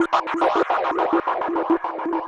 Index of /musicradar/rhythmic-inspiration-samples/105bpm